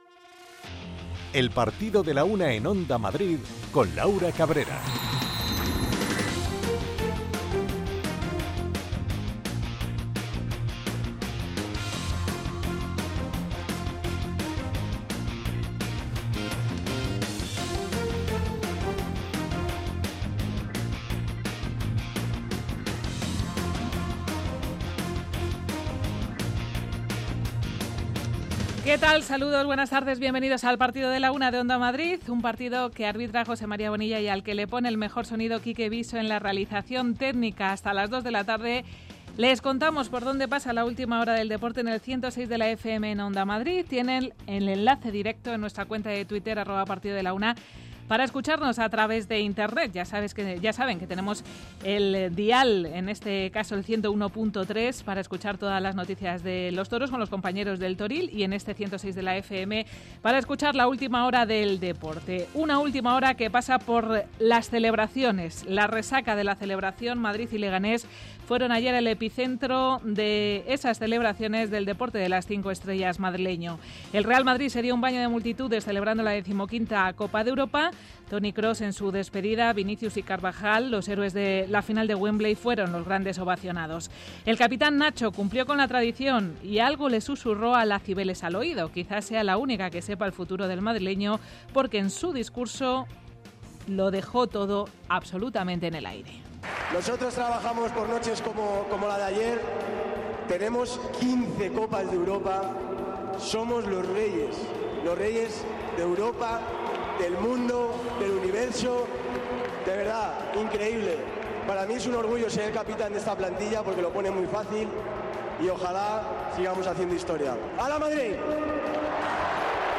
Rescatamos los mejores sonidos de la celebración del Real Madrid por la consecución de la decimoquinta Liga de Campeones.